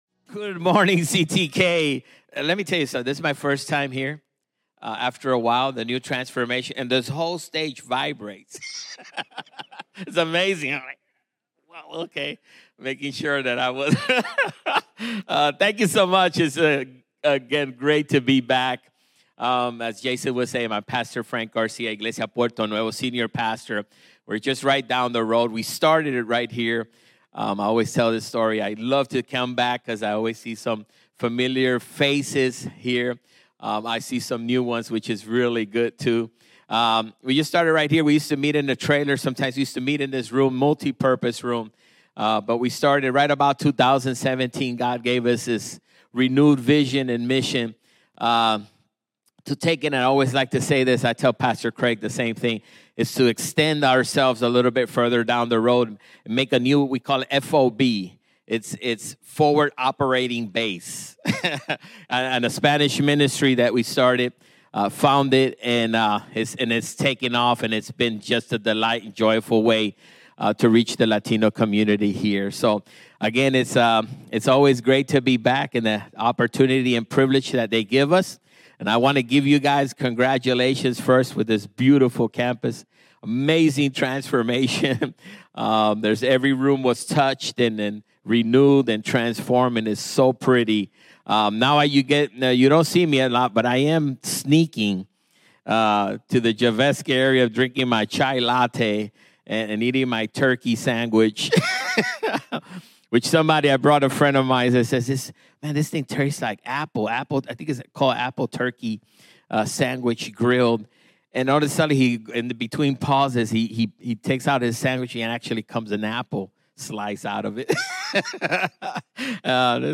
CtK-Sermon-1.mp3